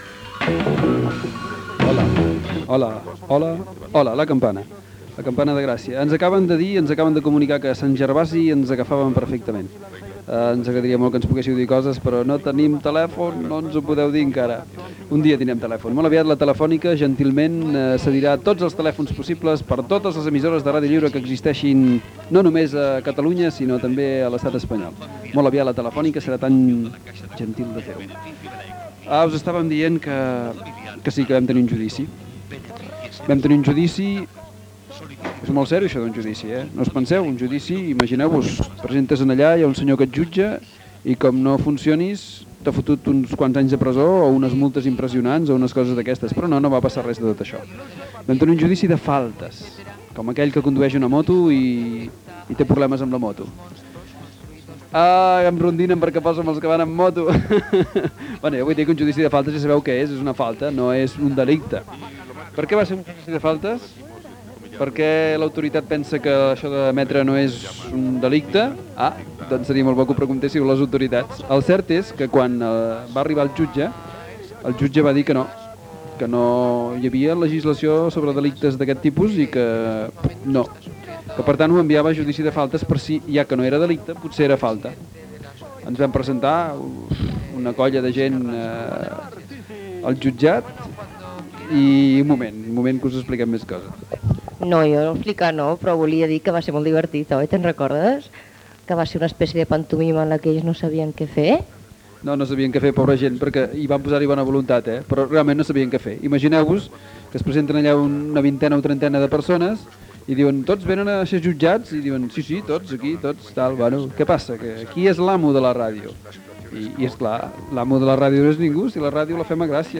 Esment al judici de faltes a integrants detinguts el mes de gener de La Campana i crítica al moviment d'emissores municipals per no criticar-ho. Tema musical i programa de cuina.
FM